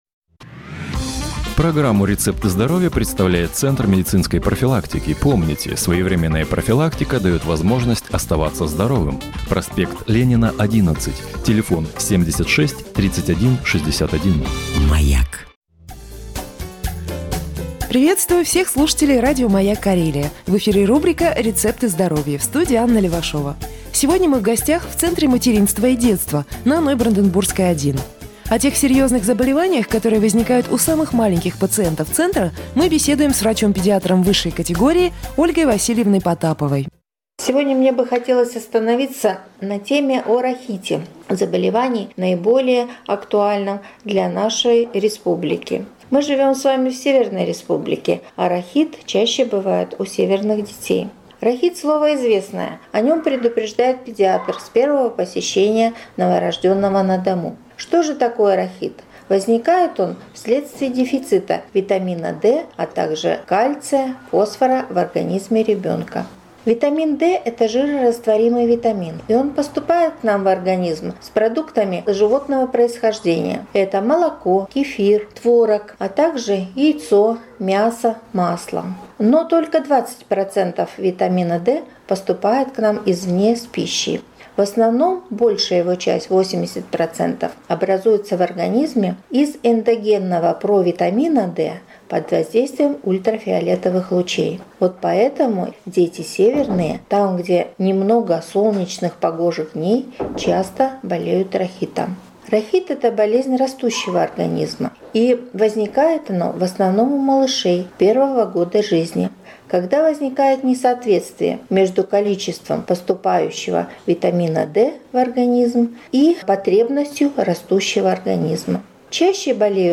С какими серьёзными заболеваниями сталкиваются в первые годы жизни самые маленькие пациенты? На этот и другие вопросы отвечает специалист «Центра материнства и детства»